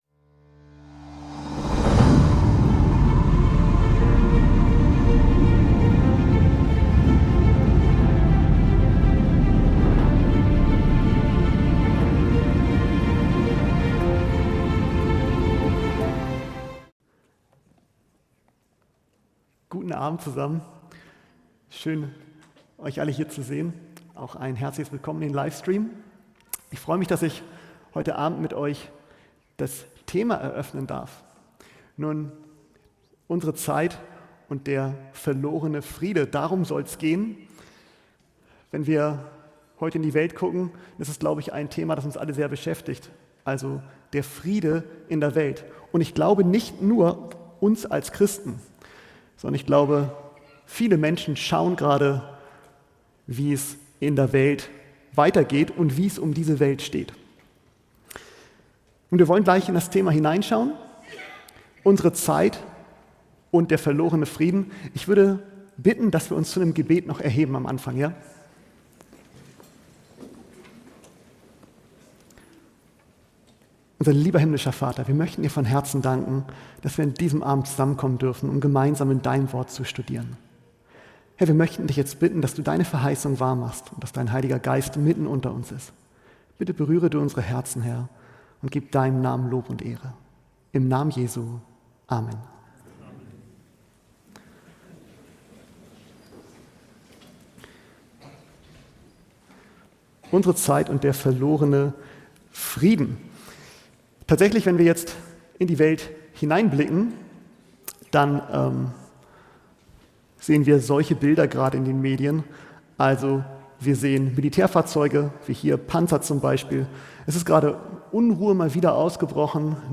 In diesem packenden Vortrag wird die aktuelle Weltlage und der schleichende Verlust des Friedens beleuchtet. Angesichts wachsender Kriege und gesellschaftlicher Unruhen wird die Botschaft von Hoffnung und Glauben hervorgehoben, die uns leitet, auch in Krisenzeiten.